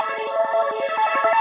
Index of /musicradar/shimmer-and-sparkle-samples/170bpm
SaS_MovingPad03_170-A.wav